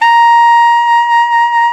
SAX ALTOMP0O.wav